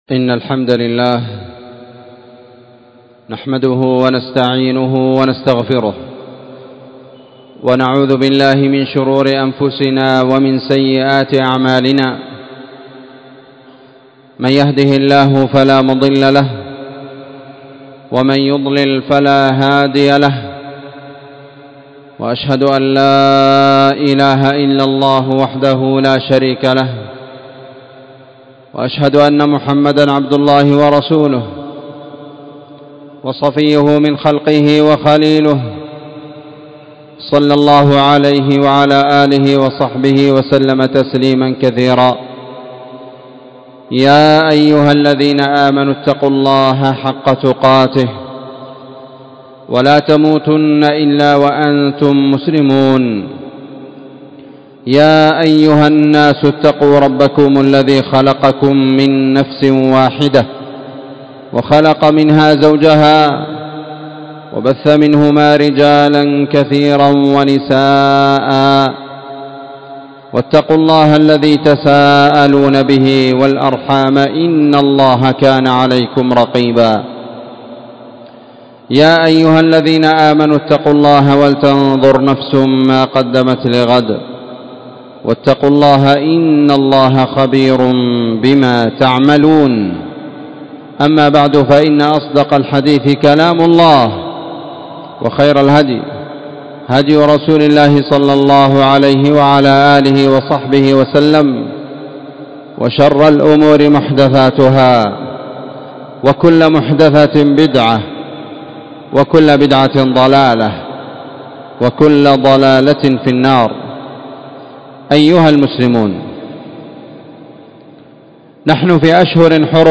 التنويه بالحُجة إلى فضل عشر ذي الحجة خطبة ٢٧ ذي القعدة ١٤٤٤
خطبة جمعة
في مسجد المجاهد- النسيرية- تعز